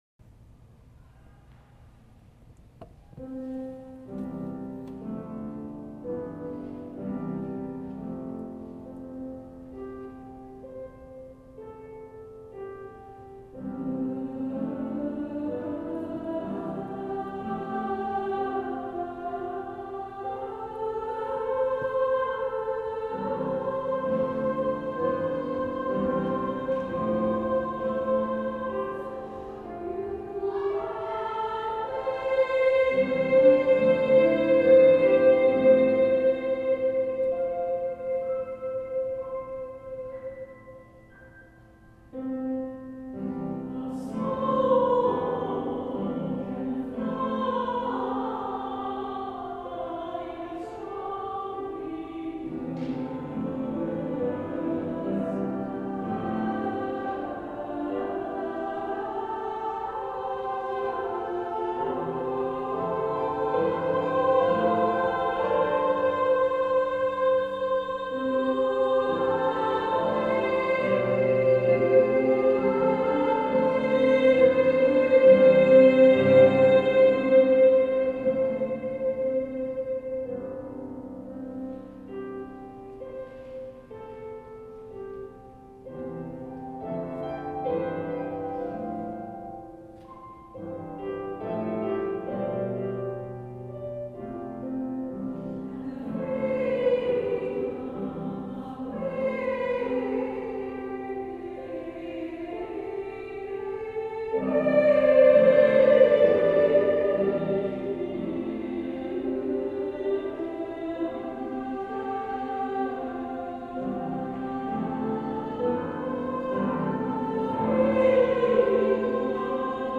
Two-Part Chorus of Mixed or Equal Voices (divisi) and Piano